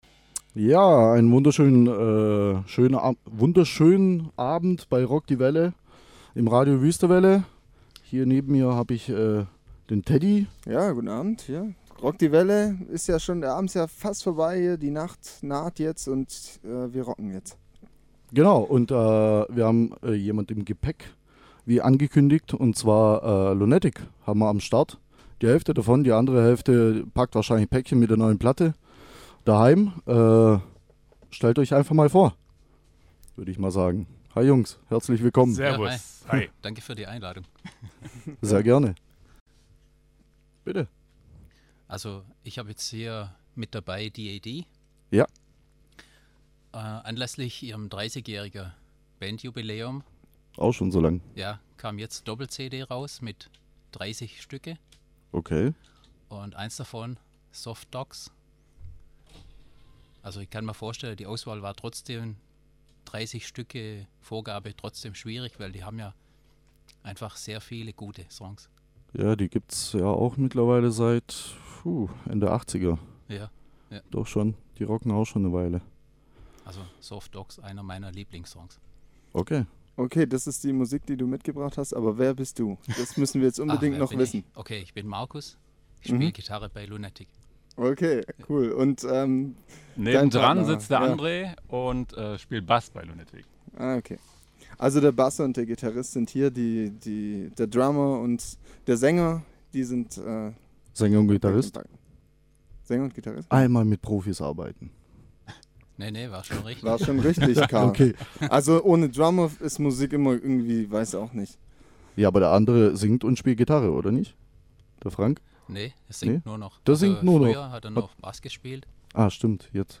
Der Anlass war die neue Scheibe "Over The Moon" mit satten 19 Songs. So durften wir der Band ein Paar Infos zur neuen Scheibe entlocken und die Jungs freuten sich, dass sie ein Paar Songs von ihren Lieblingsbands laufen lassen konnten.